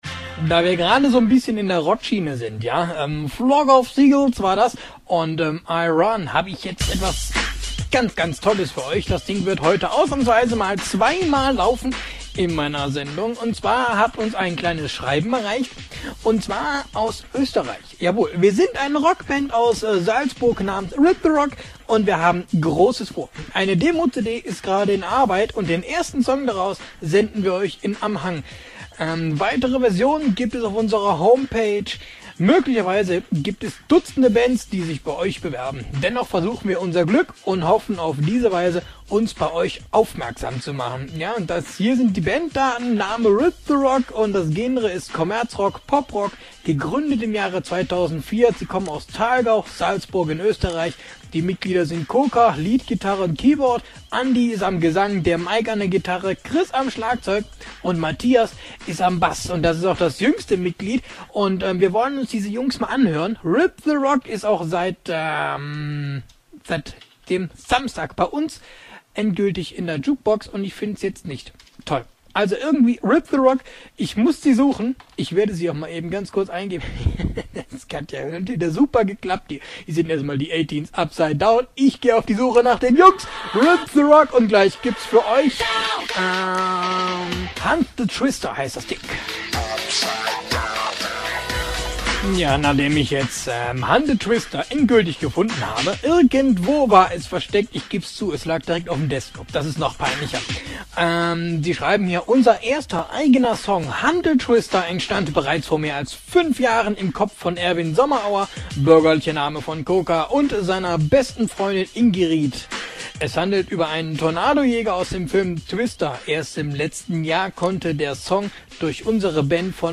Mitschnitt-Radioauftritt